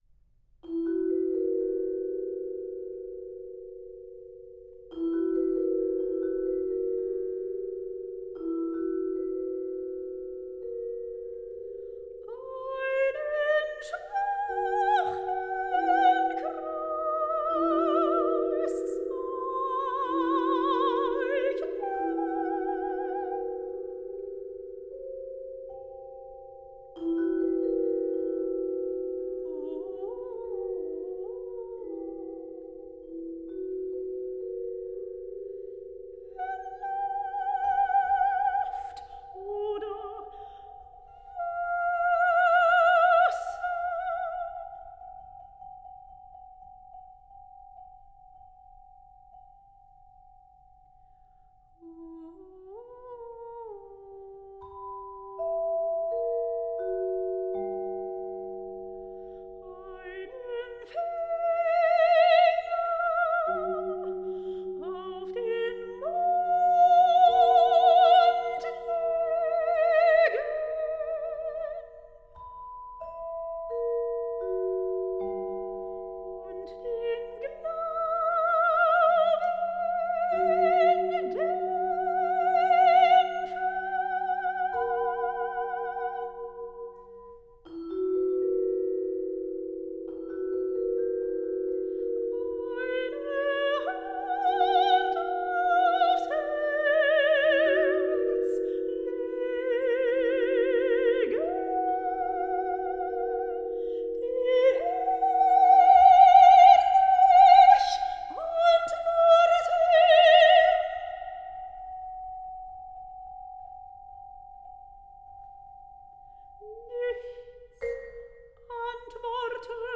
for soprano and vibraphone (6 min)